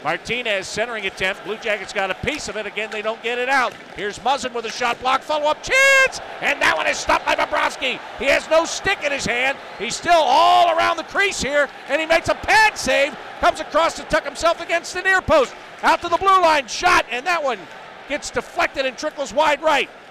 Sergei Bobrovsky with saves at the end of the first to keep the game at 0-0 against the LA Kings, Tuesday December 8th inside Nationwide Arena.